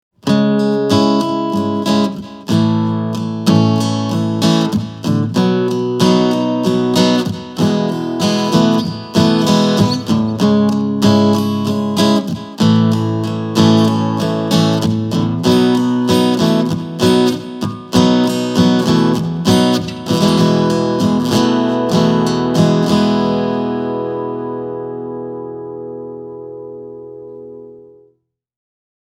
Vahvistimesta lähtee jo ilman että koskee EQ:hun raikas ja tuore, muttei missään nimessä yli-innokas sointi (kaikki esimerkit soitettu Taylor 110CE -kitaralla):
Yhdellä vakioajalla varustettu viive-efekti tuo kyllä mukavasti syvyyttä kuvaan, eikä sen soundissakaan ole valittamista, mutta yksi tempo ei sovi kaikille biiseille yhtä hyvin:
T6 – Delay-viive
delay.mp3